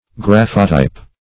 Graphotype \Graph"o*type\, n. [Gr. gra`fein to write + -type.]